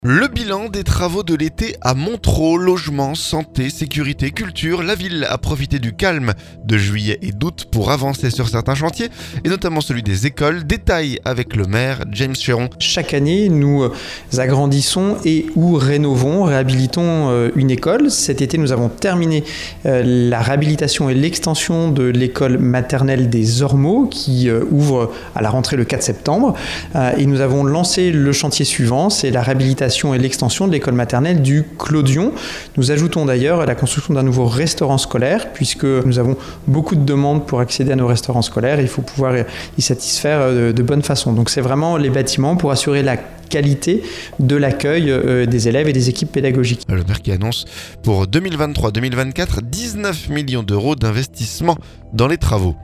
La ville a profité du calme de juilllet et d'août pour avancer sur certains chantiers. Et notamment celui des écoles. Détails avec le maire James Chéron.